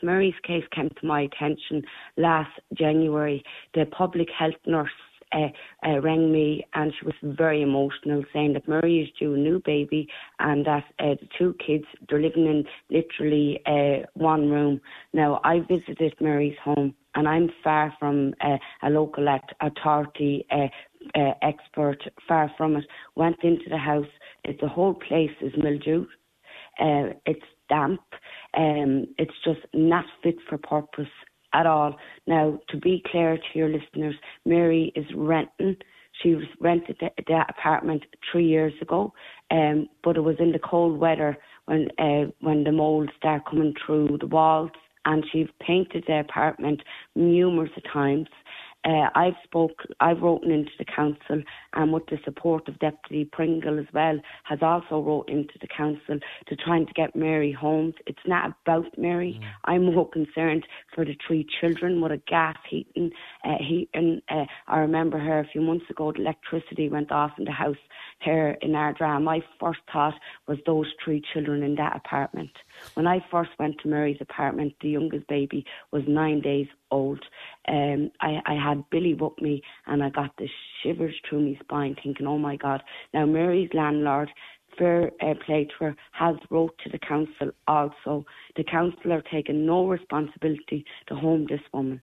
Senator Eileen Flynn told the Nine til Noon Show that the current system is not fit for purpose: